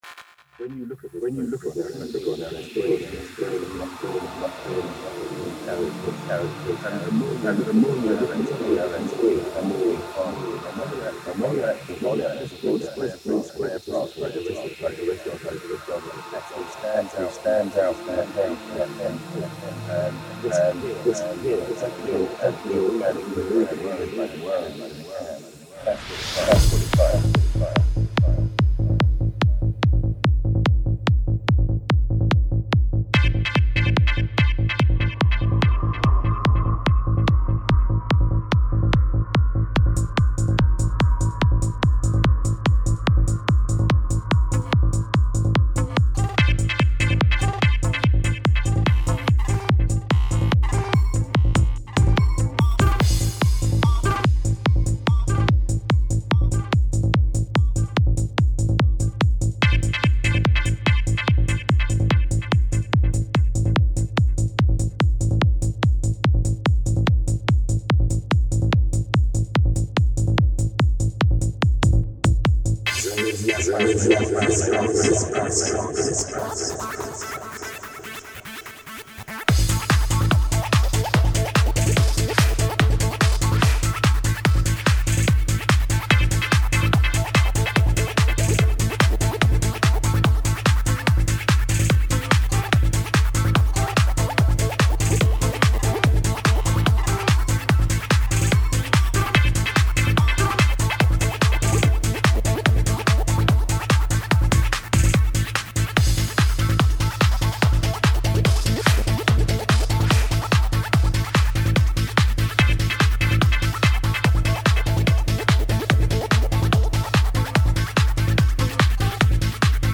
Première communauté française sur la musique trance et goa.
bon p'tit son psy
la ligne de bass manque de profondeur (mix?)/ (ou octave) versus kick